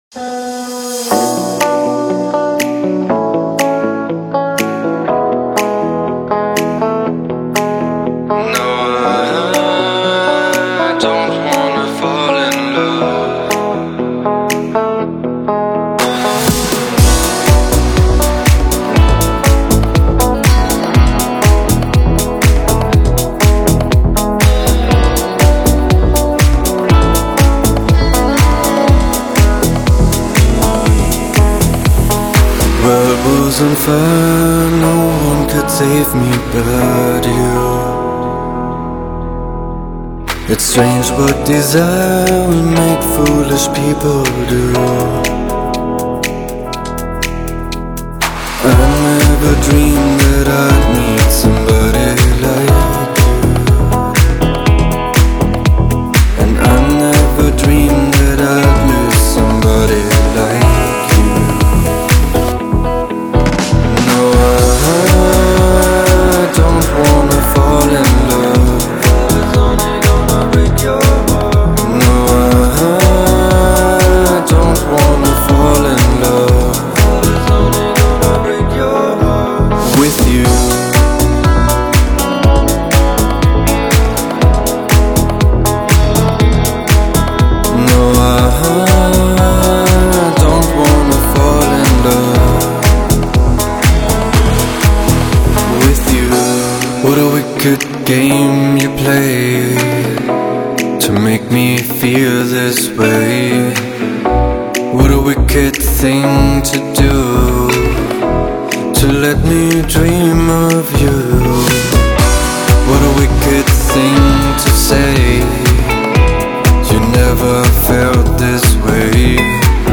• Жанр: Dance